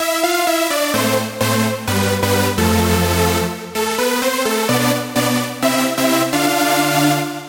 Power Bass Electro House Synth (SAW+SQUARE)
描述：Electro bass hook progression.
Tempo is 128 bpm so download it and get to work
标签： movement progression saw electro progressive 128 synth bpm house dance electronic bass square
声道立体声